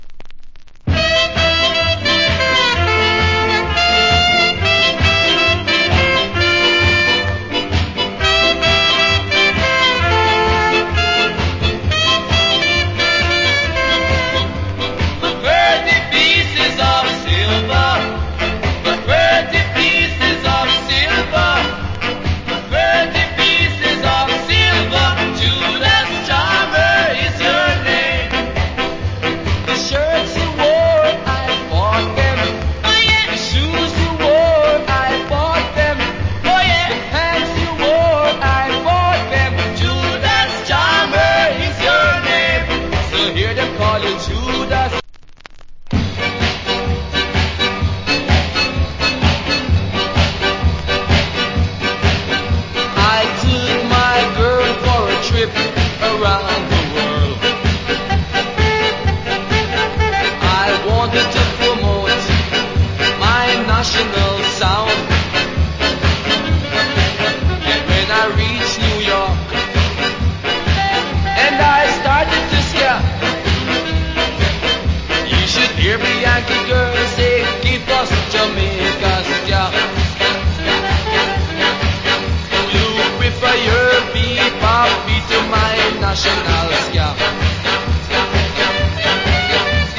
category Ska
Wicked Ska Vocal.